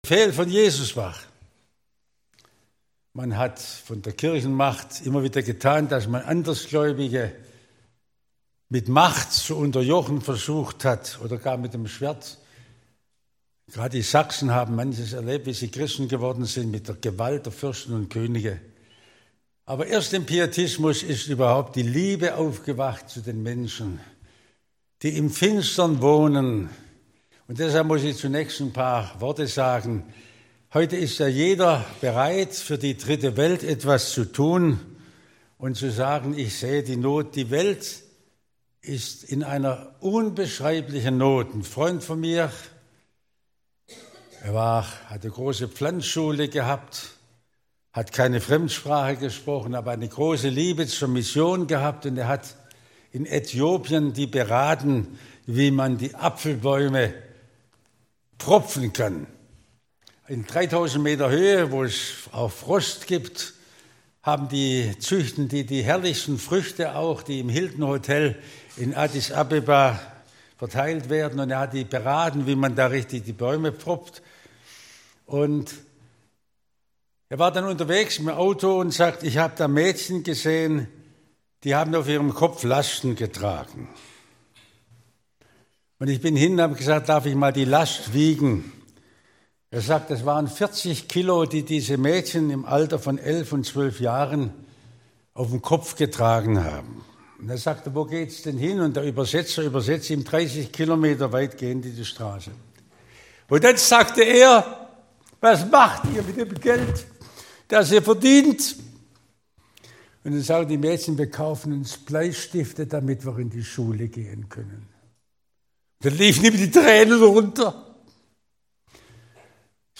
Missionsvortrag